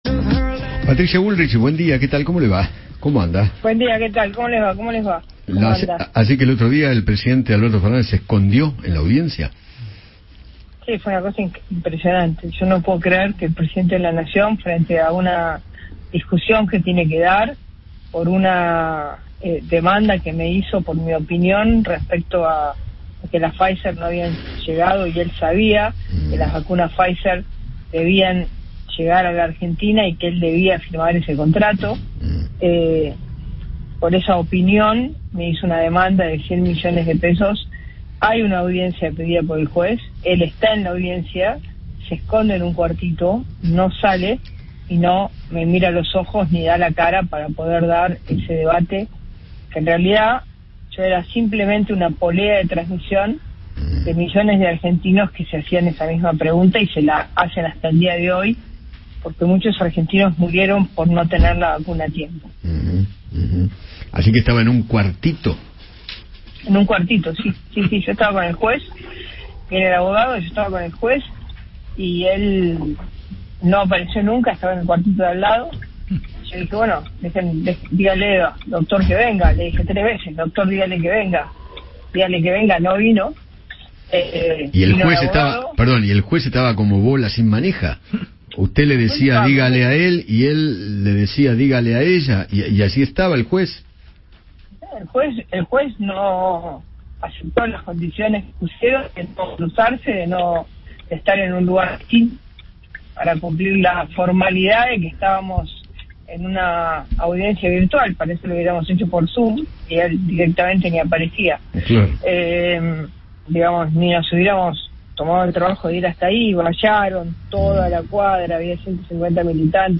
Patricia Bullrich dialogó con Eduardo Feinmann sobre la audiencia judicial con Alberto Fernández y aseguró que el mandatario “se escondió y no me miró a los ojos”.